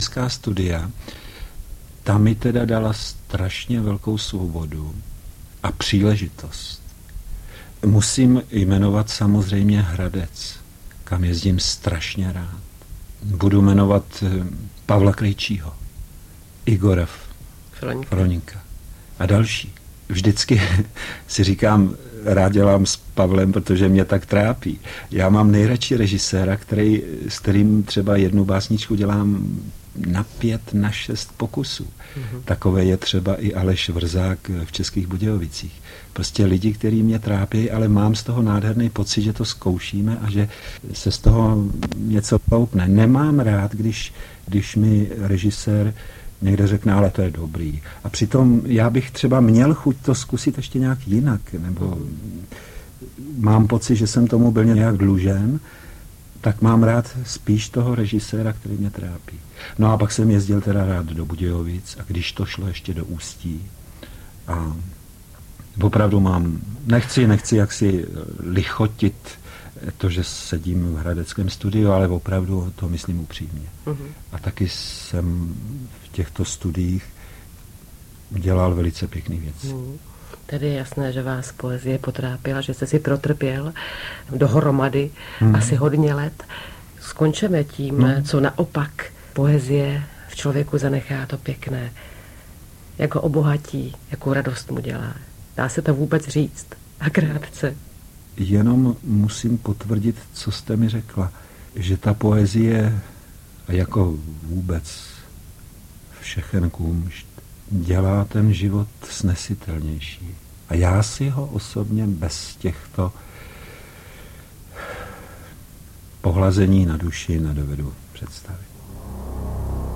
rozhovor pro ČR Hradec Králové